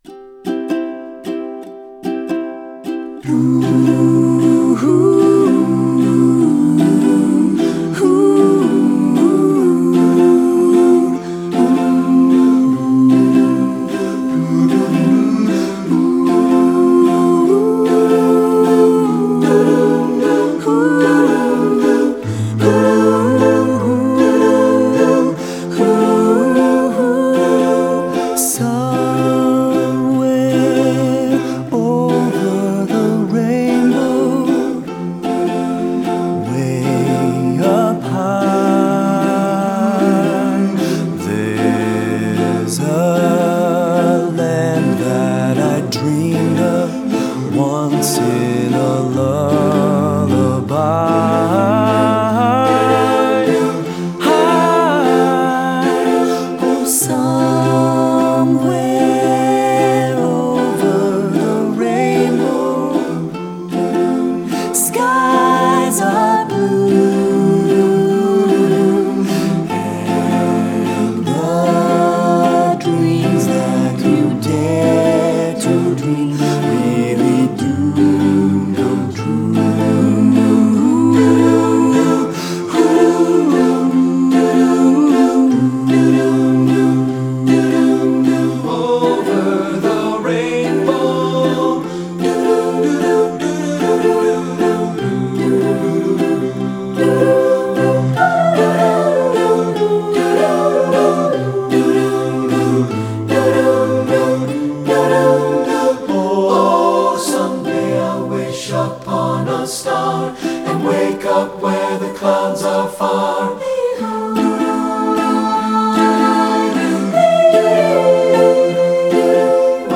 choral
TTBB (SATB recording), sample